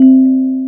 MusicBox_C4_22k.wav